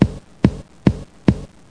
00418_Sound_Klopf.mp3